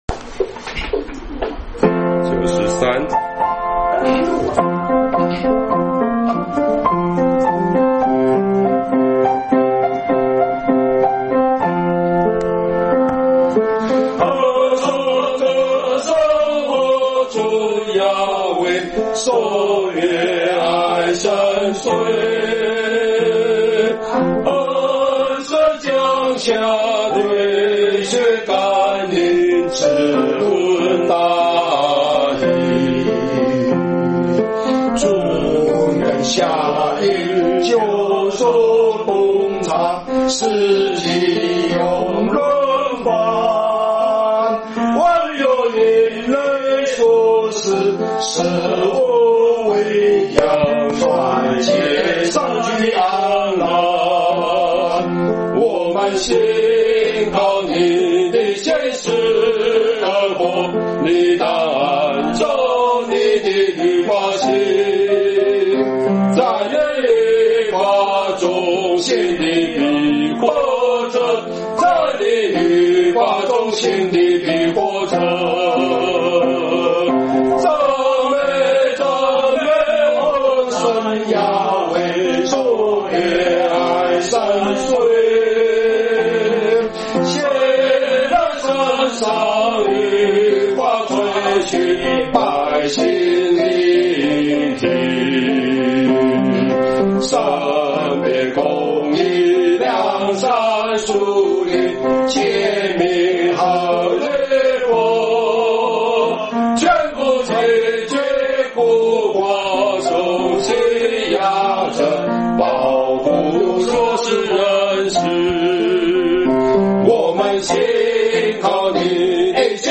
詩歌頌讚